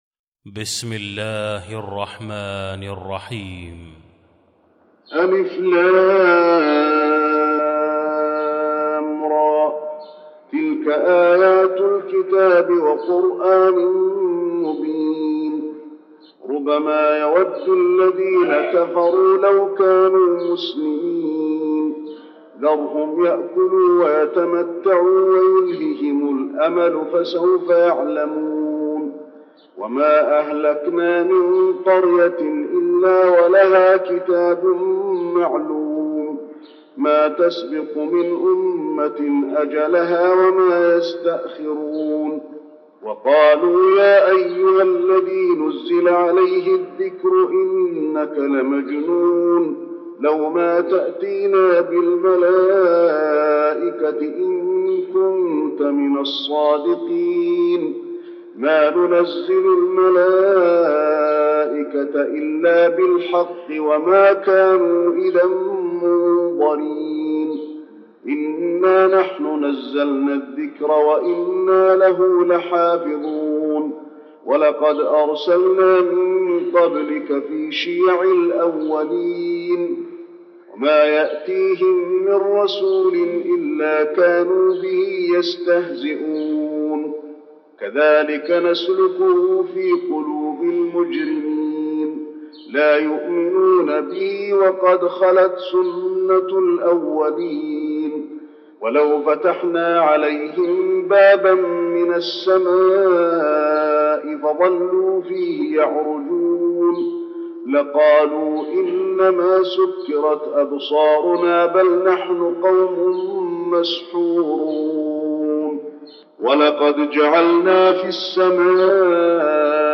المكان: المسجد النبوي الحجر